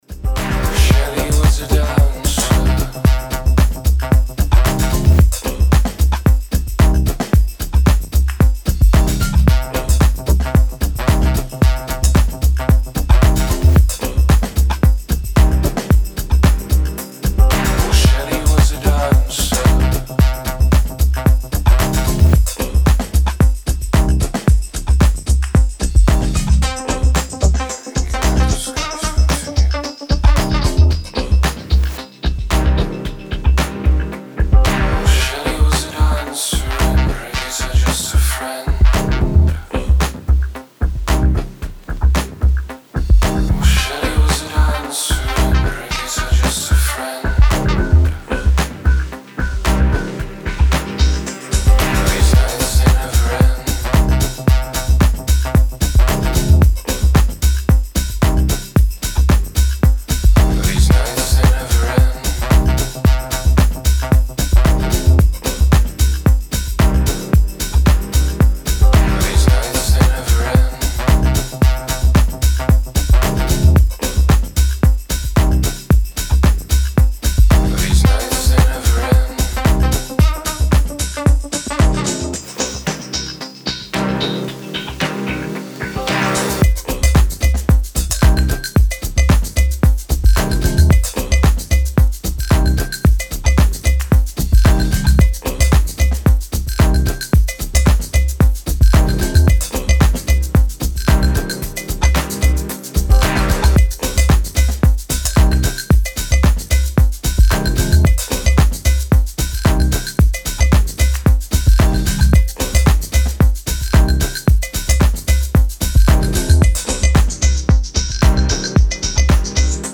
Style: Tech House / Minimal